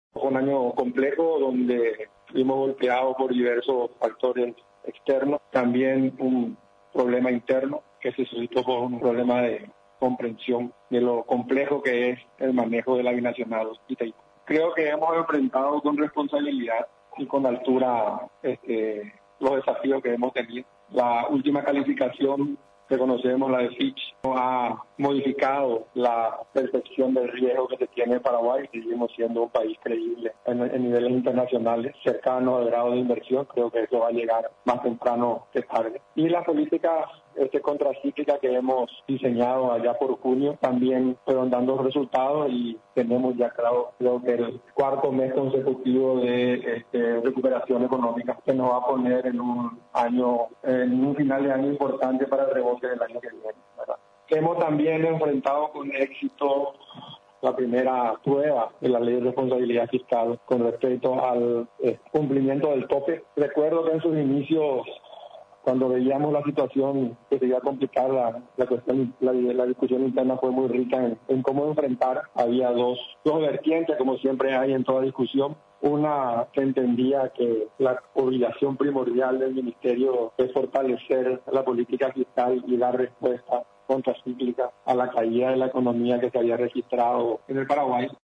Durante el informe anual, el secretario de Estado, recordó que uno de los factores de la recesión en la economía en el Paraguay, fue lo ocurrido en el Brasil y la Argentina, además de la crisis desatada a consecuencia del acuerdo bilateral sobre cesión de energía eléctrica al país vecino.